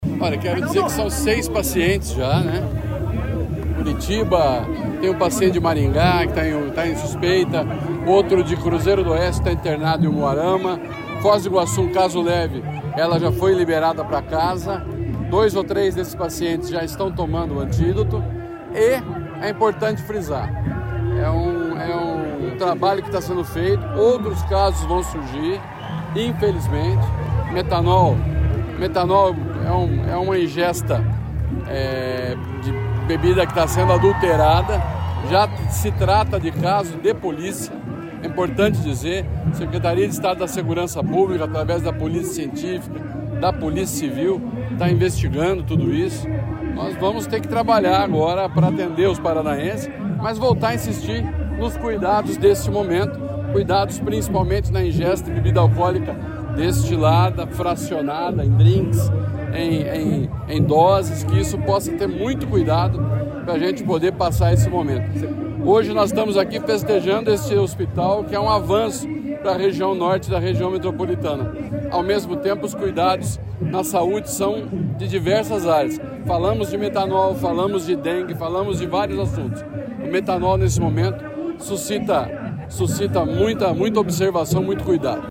Sonora do secretário da Saúde, Beto Preto, sobre as atualizações dos casos de intoxicação por metanol no Estado